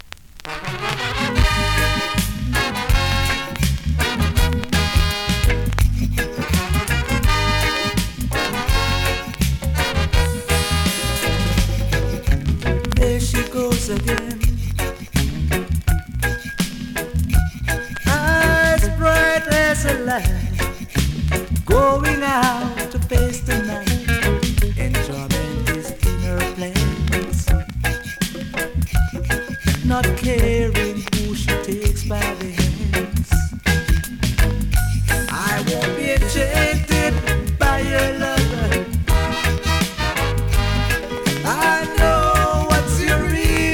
スリキズ、ノイズそこそこありますが
※VERSION(B面)の試聴はございません。